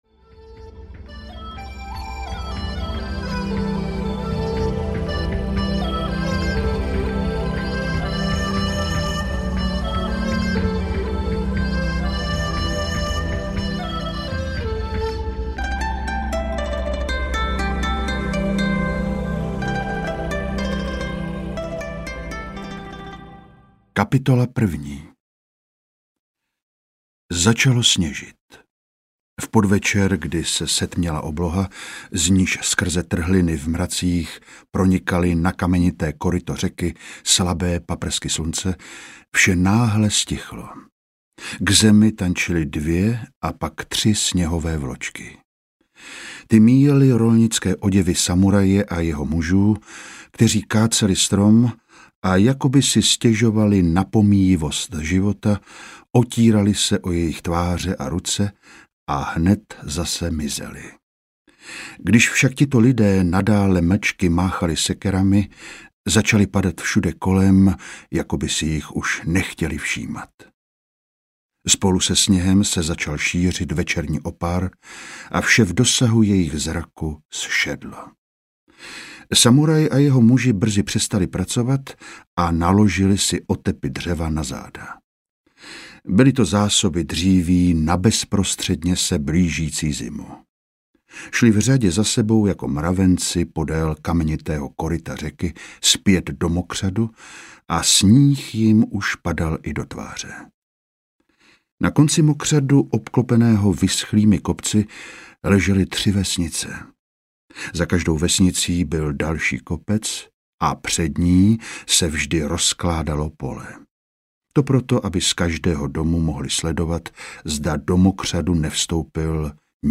Samuraj audiokniha
Ukázka z knihy